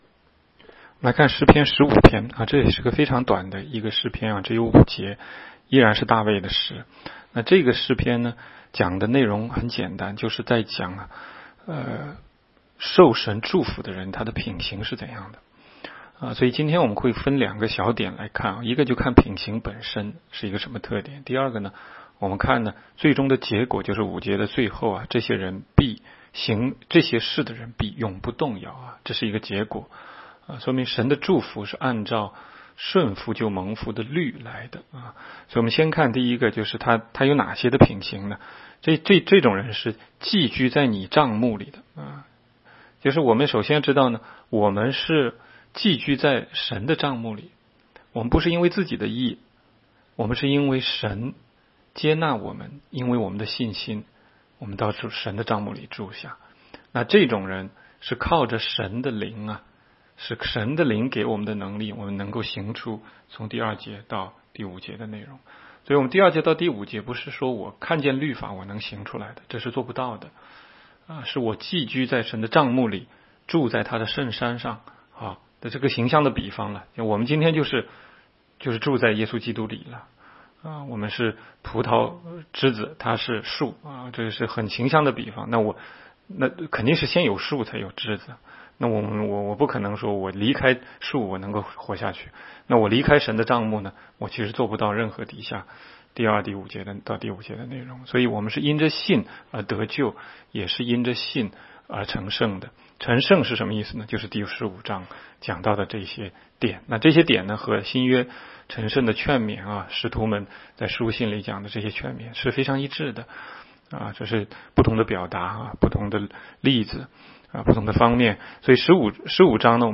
16街讲道录音 - 每日读经-《诗篇》15章